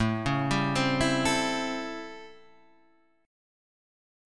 A7#9 chord {5 4 5 5 5 3} chord